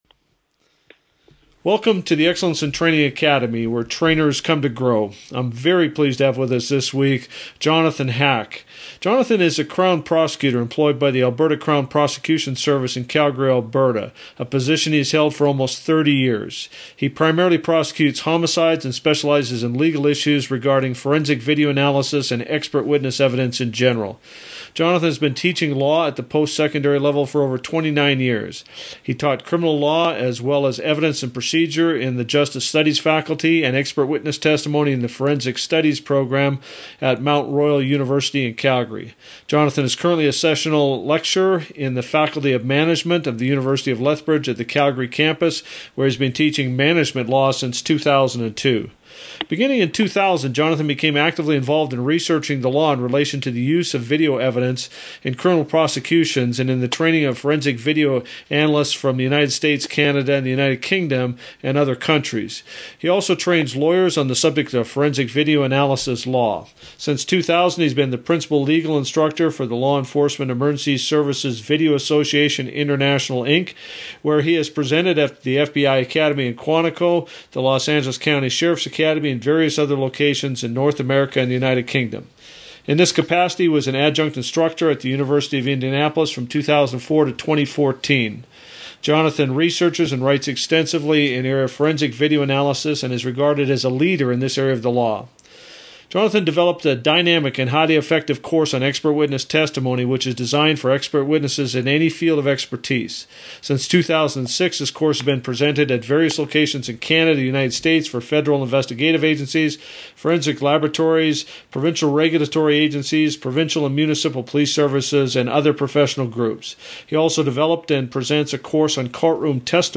Audio Interview: Keys to Effective Expert Witness Testimony